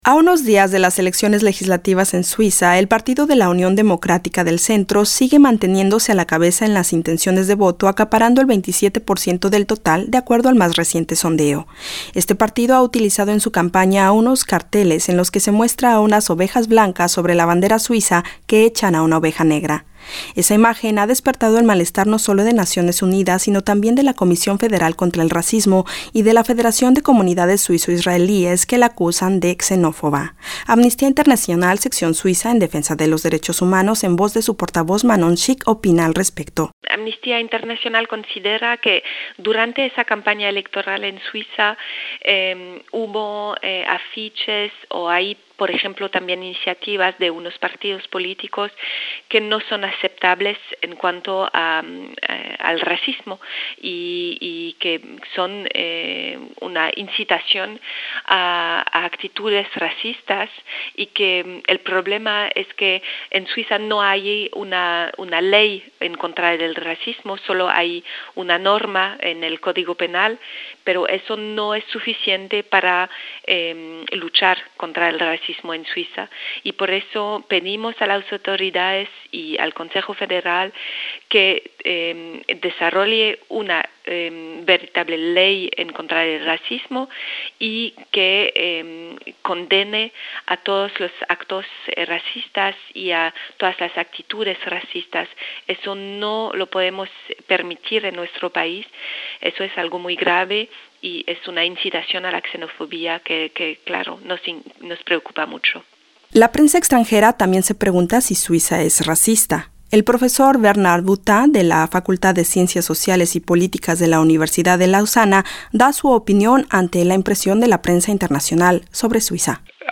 Por ello, subraya la importancia de mostrar una señal clara de la apertura del país con la mayor proporción de población inmigrante del continente (21.8%). Reportaje